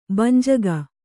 ♪ banjaga